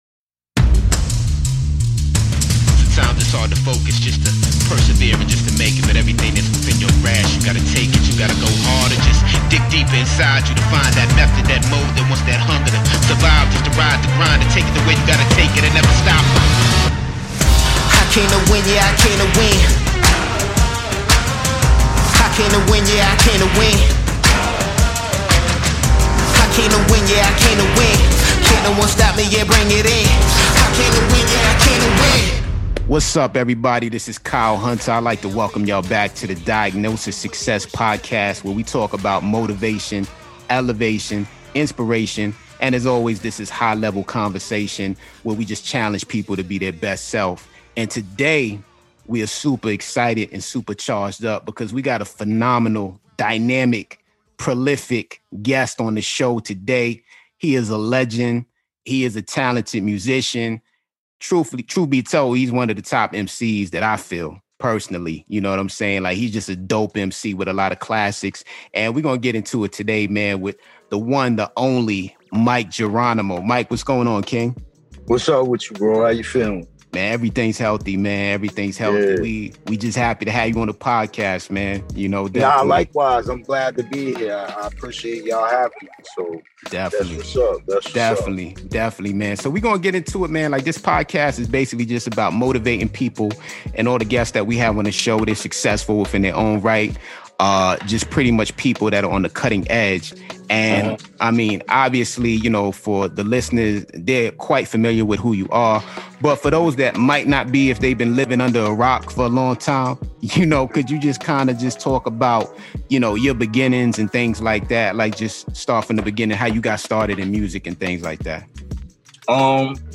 In this episode we have Part 1 of our Diagnosis Success interview with Mic Geronimo. Mic discusses his come up in the music industry, past collaborations, and also discusses the importance of artists remaining true to themselves.